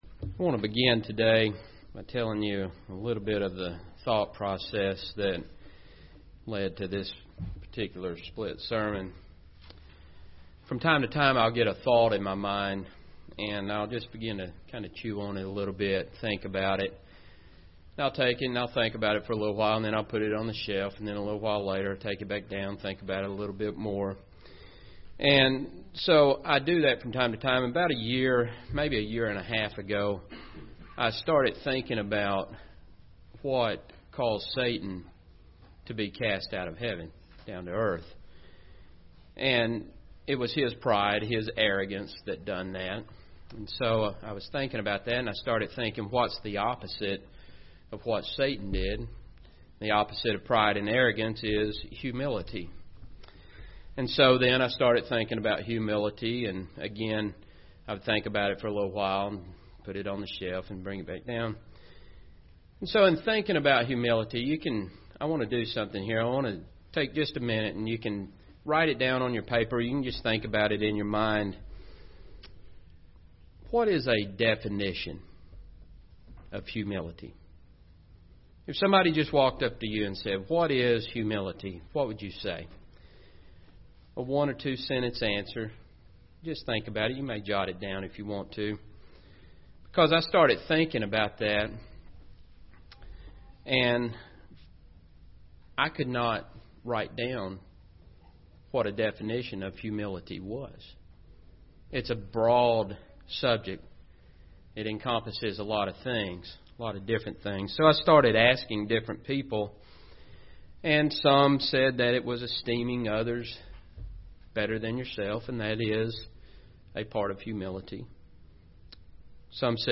Humility is the degree to which we rely upon God. This sermon discusses the topic by looking at positive and negative examples God servants in the Bible.